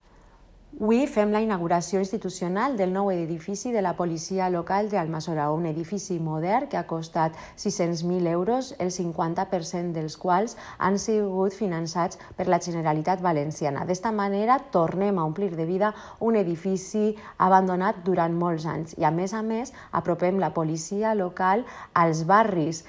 L’alcaldessa d’Almassora, Merche Galí: